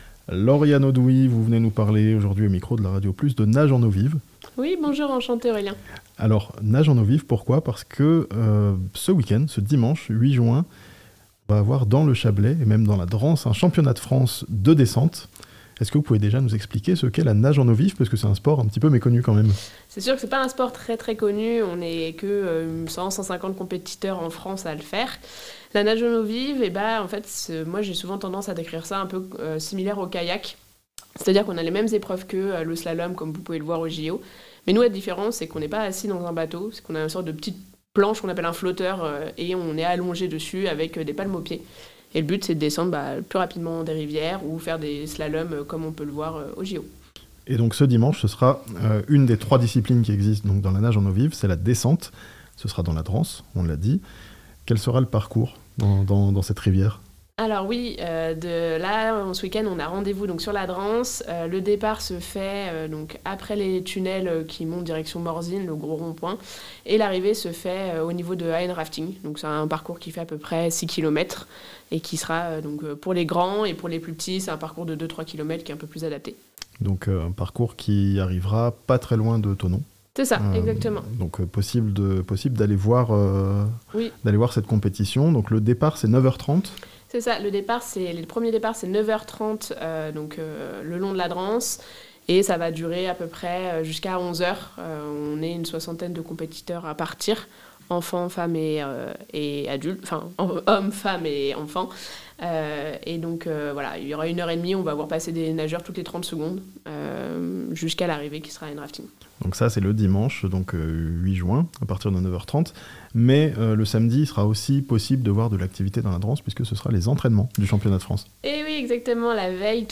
Le championnat de France de nage en eau vive, dans le Chablais ce week-end (interview)